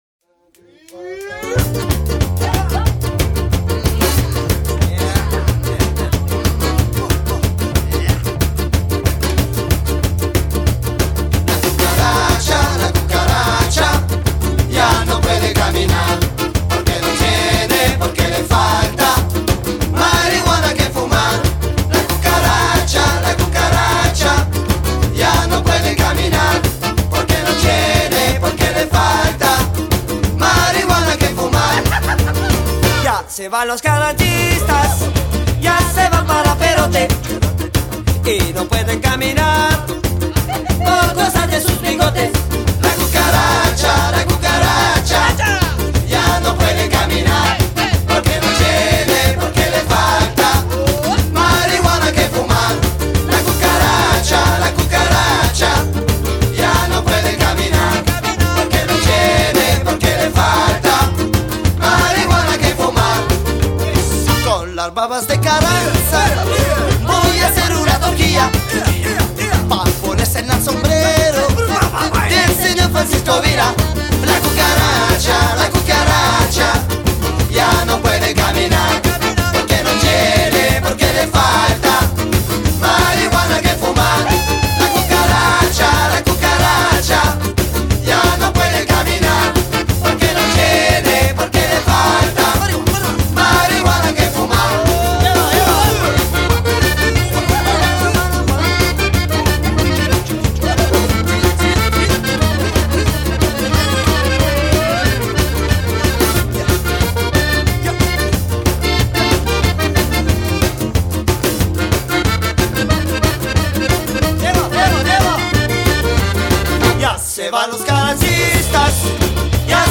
На русском, древняя (лет 60) запись